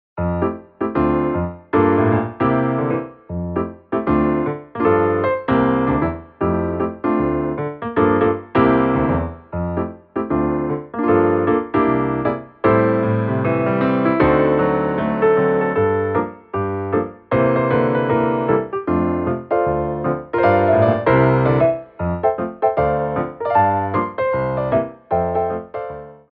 TACIT & STOP TIME